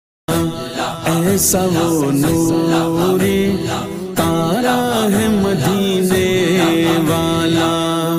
naat recording